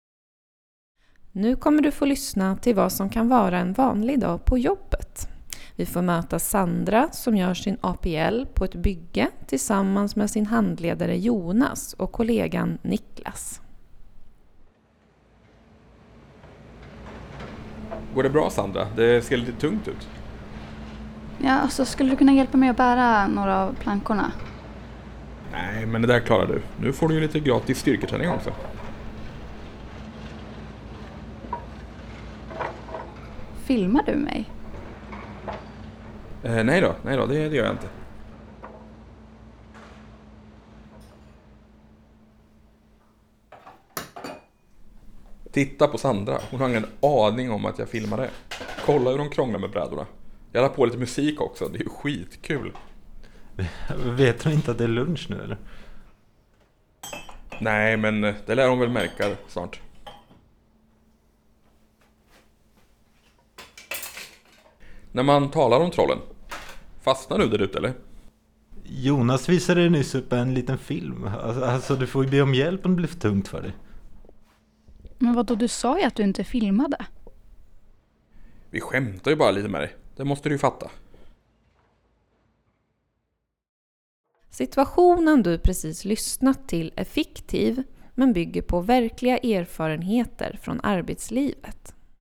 Övningen fokuserar på fem olika scenarion i radioteaterform som bygger på erfarenheter från svenska arbetsplatser.